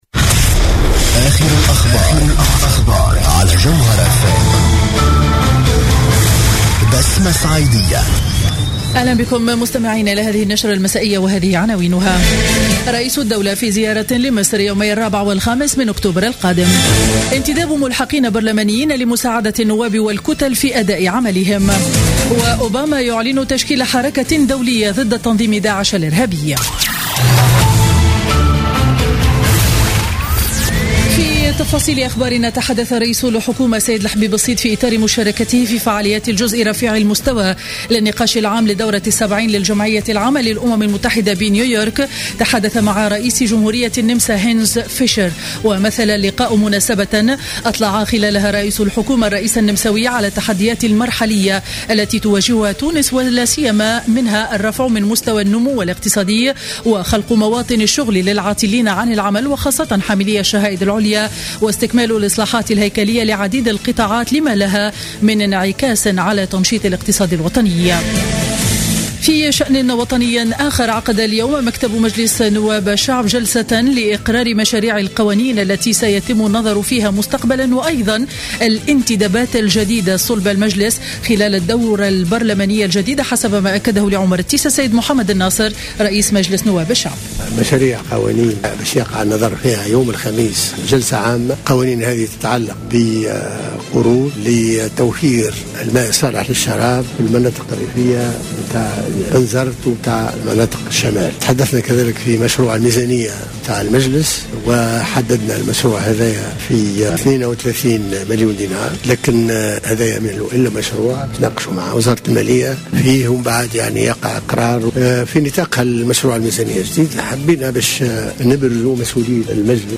نشرة أخبار السابعة مساء ليوم الثلاثاء 29 سبتمبر 2015